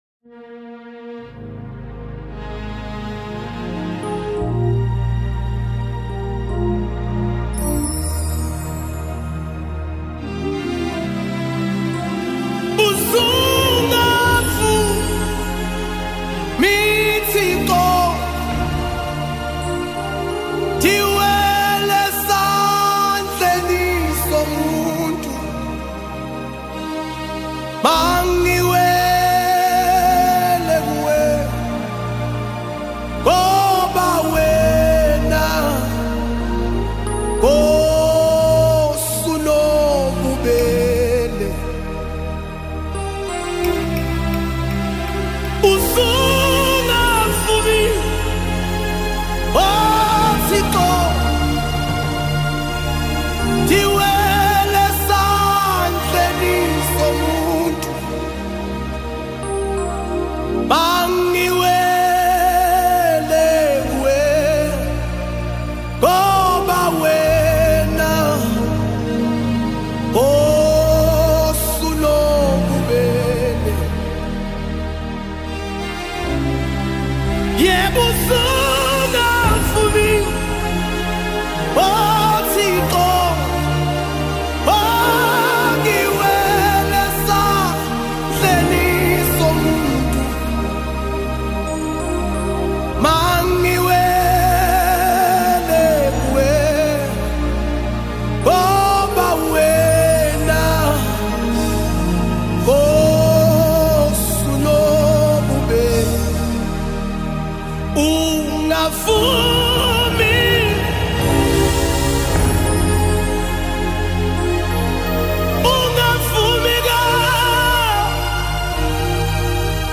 singer and songwriter